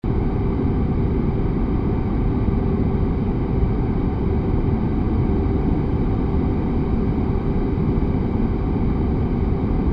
TheExperienceLight / sounds / Cosmic Rage / general / combat / aircraft / idle.wav
idle.wav